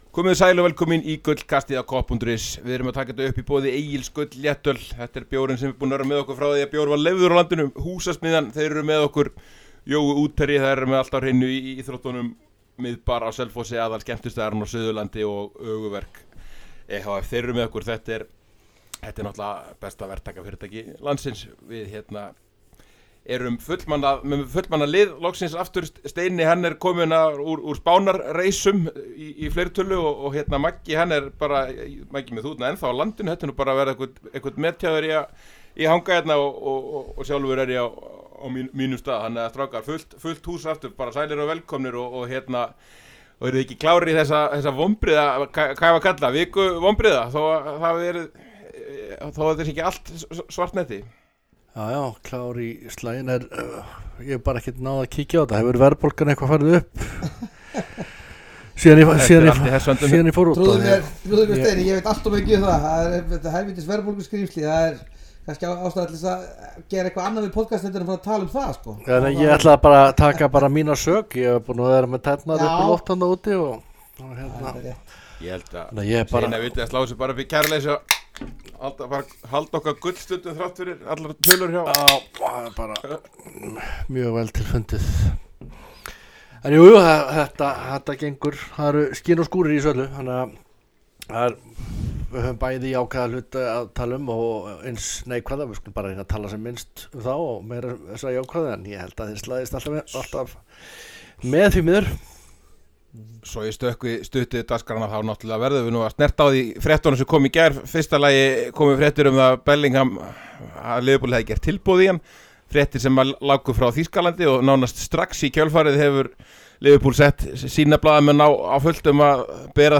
Feliz Bergsson mætti með okkur á nýjan og endurbættan Sólon í Miðbænum og fór yfir allt það helsta í þessari viku.